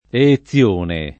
Eezione [ e-e ZZL1 ne ]